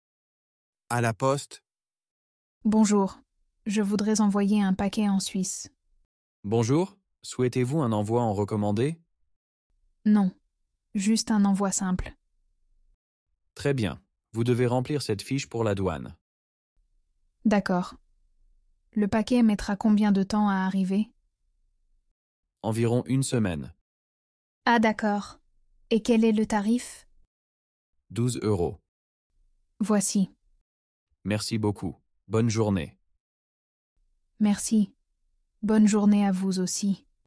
Dialogue en français – À la poste (Niveau A2)